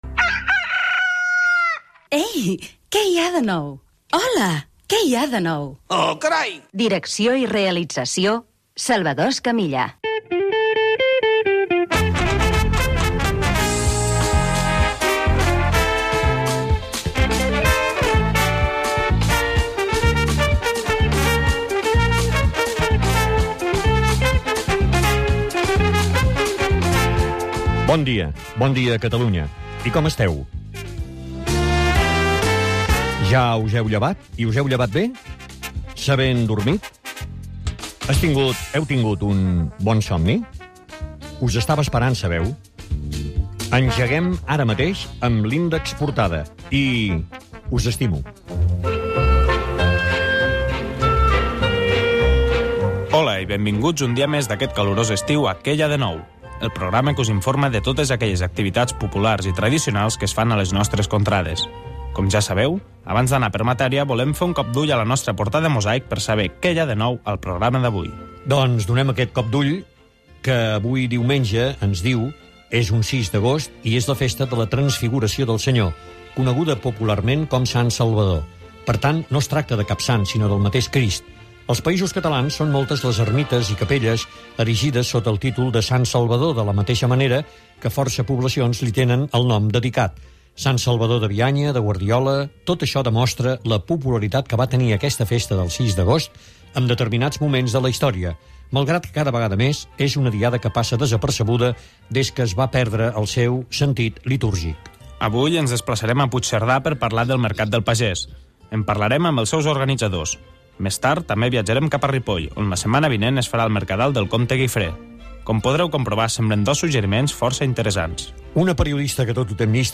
Careta, salutació, sumari del dia de Sant Salvador, telèfon del programa, tema musical